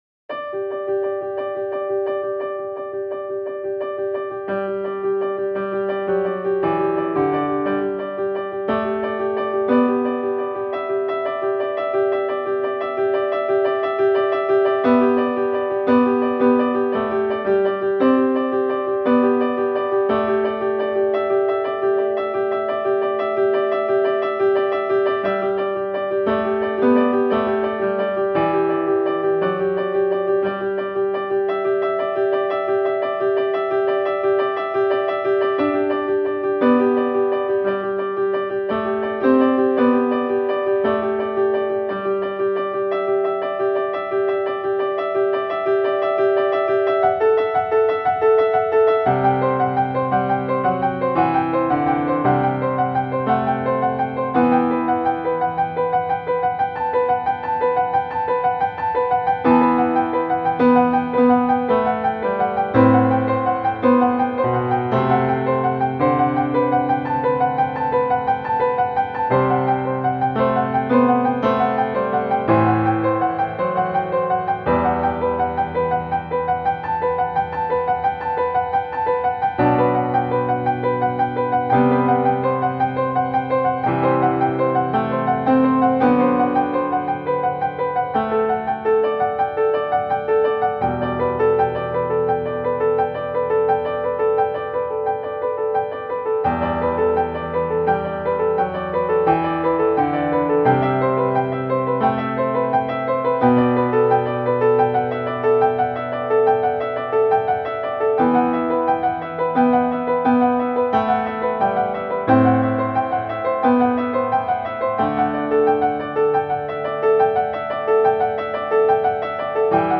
Wochenlied für den 07.06.2020